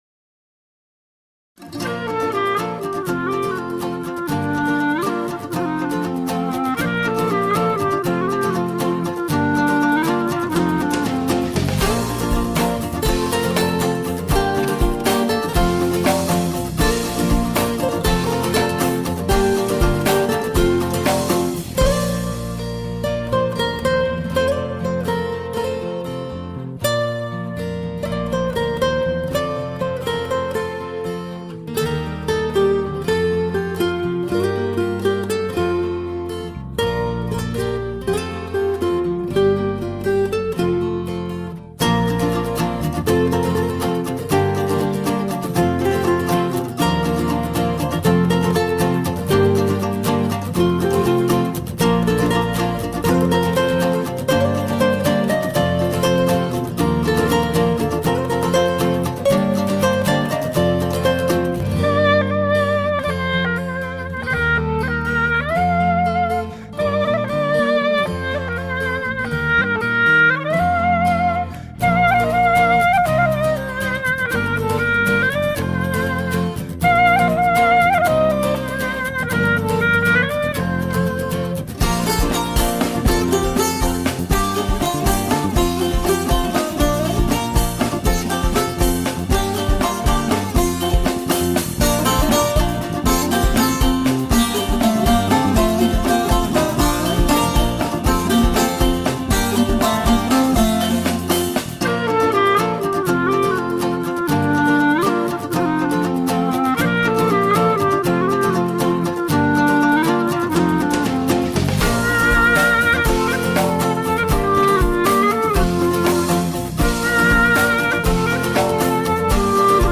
tema dizi müziği, mutlu huzurlu rahatlatıcı fon müziği.